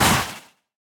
sounds / mob / breeze / hurt1.ogg
hurt1.ogg